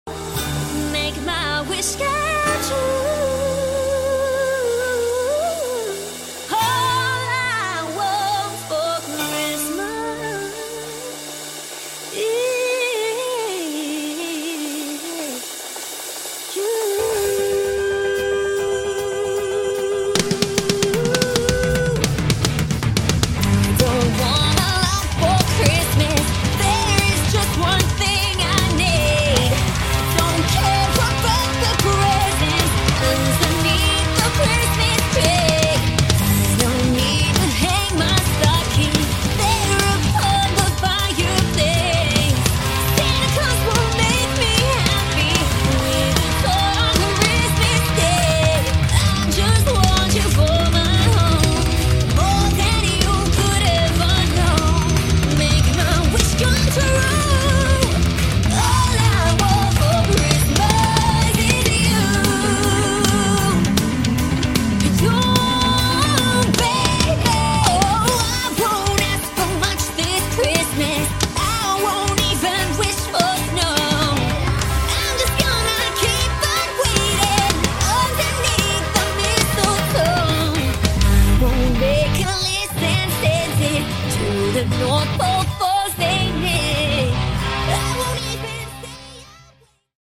Here is the same video but with the drum sound remixed.
rock cover
drum cover
Electronic drum kit
Drummer 🥁